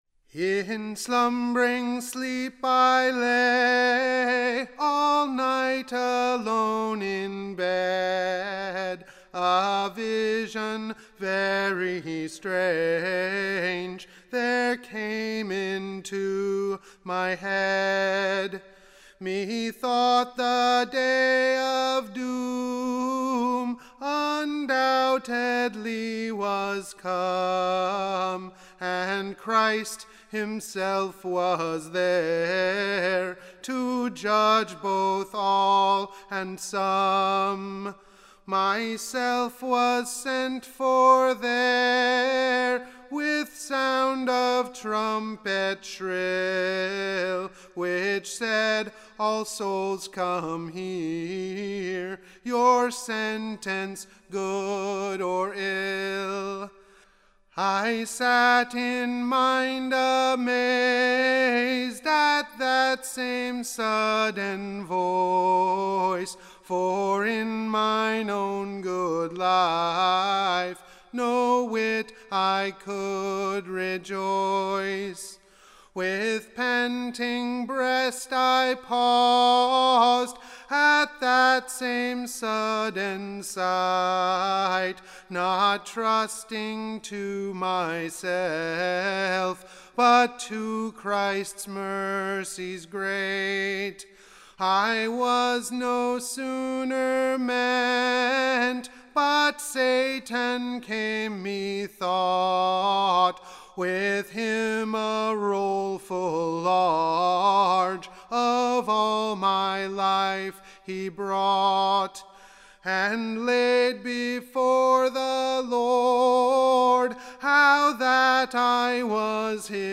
Recording Information Ballad Title A comfortable new Ballad of a Dreame of a Sinner, being very sore / troubled with the assaults of Sathan. Tune Imprint To the tune of Rogero Standard Tune Title Rogero Media Listen 00 : 00 | 6 : 48 Download P1.39.mp3 (Right click, Save As)